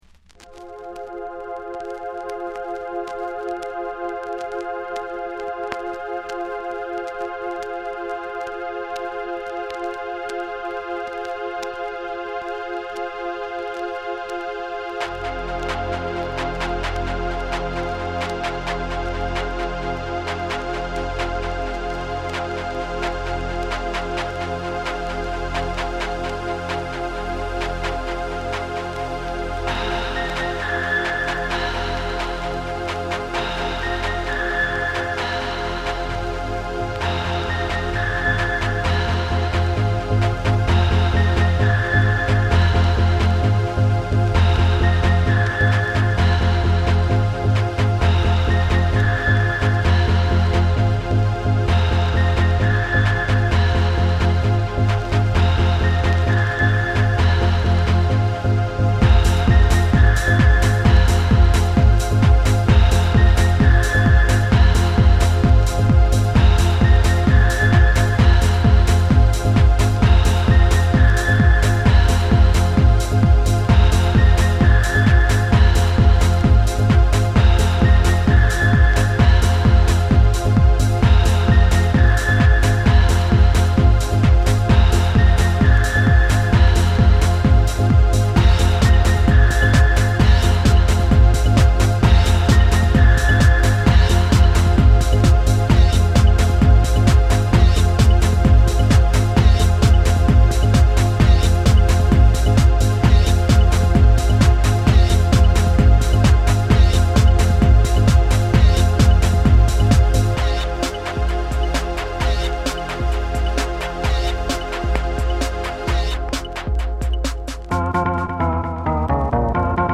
、このイナタサもぎりぎりで良いです。